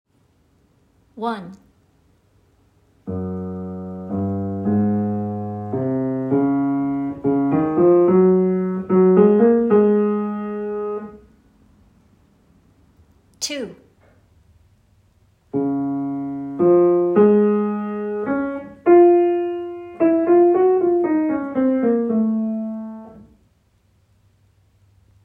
Szymanowska, Sérénade for Cello and Piano